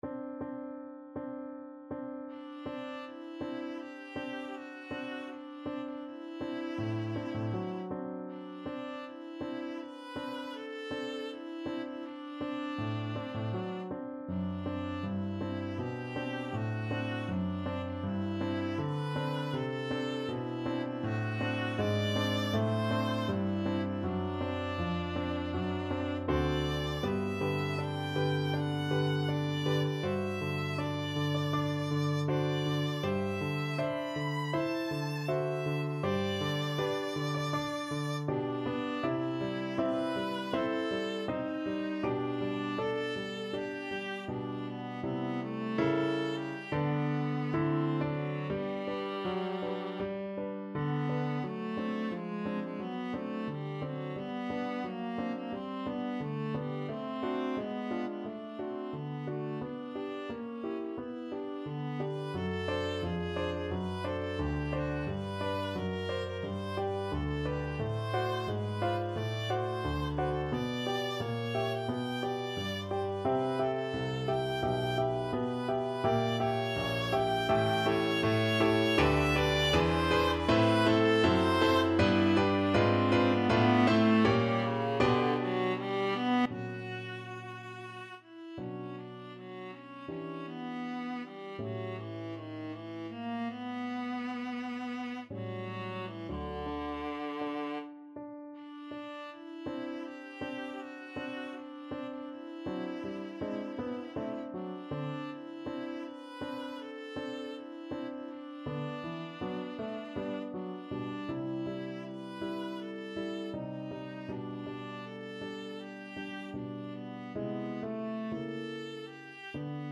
4/4 (View more 4/4 Music)
Andante espressivo
Classical (View more Classical Viola Music)